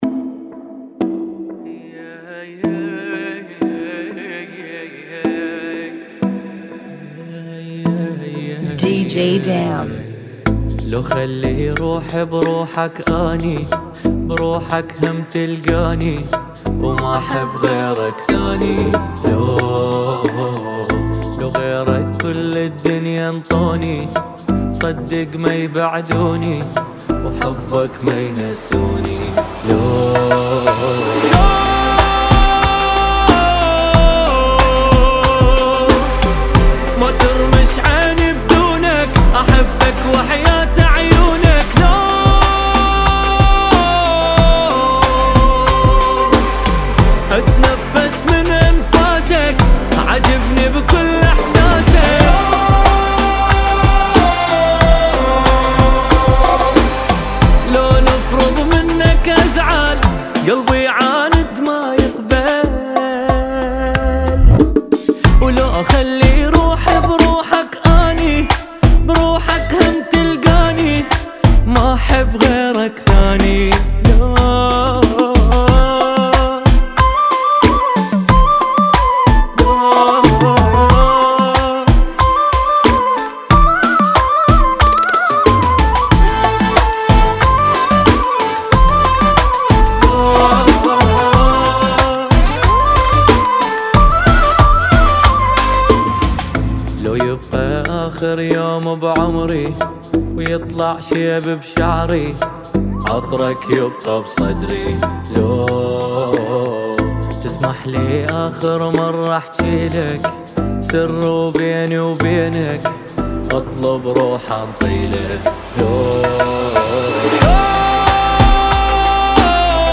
92 BPM
Genre: Bachata Remix